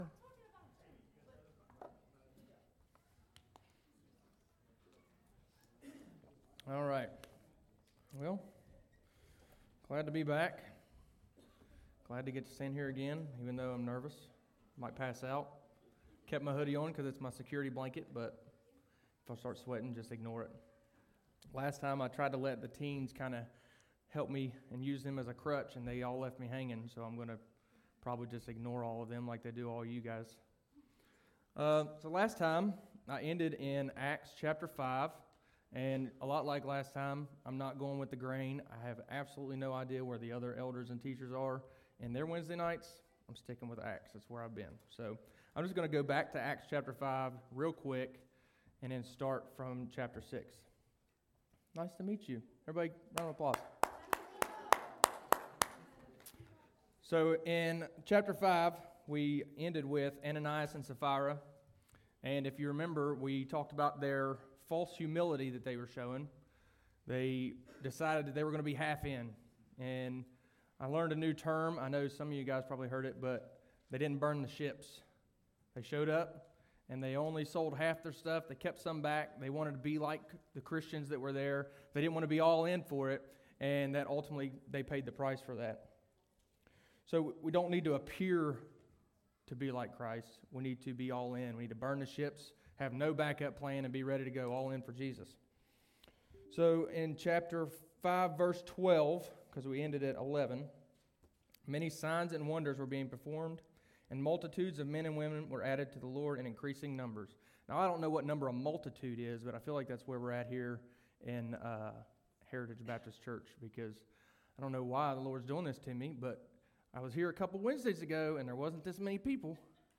Sermons by HBCWV